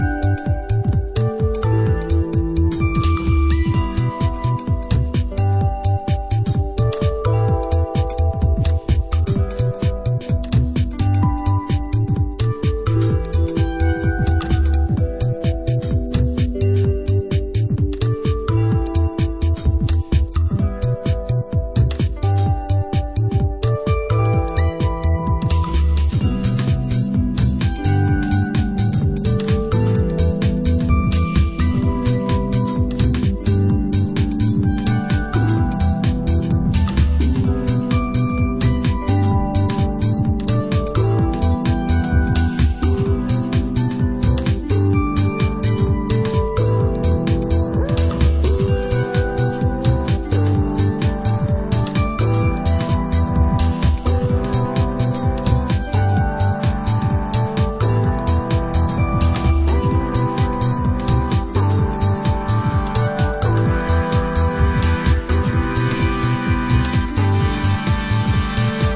San Francisco house
going for a deep and atmospheric ambient house vibe